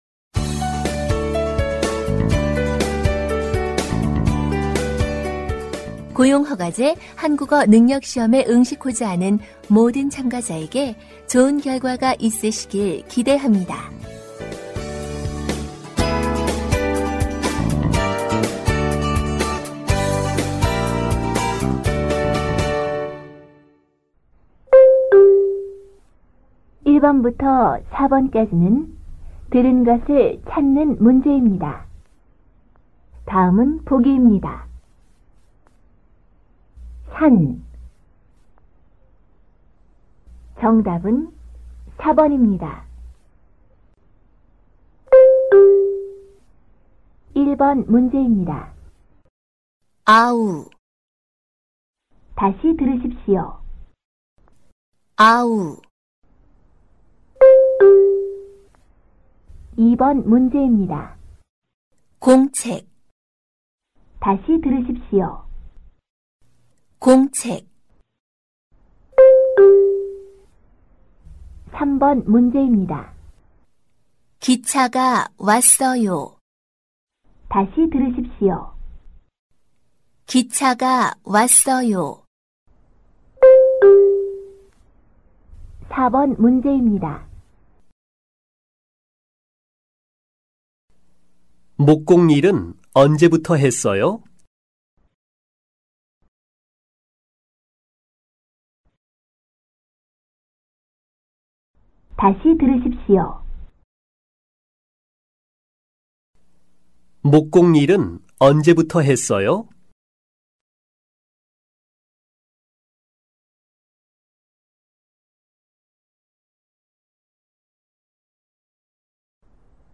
Phần Nghe Hiểu (듣기): Kéo dài từ câu 1 đến câu 25.
모든 듣기 문제는 두 번씩 들려 드립니다.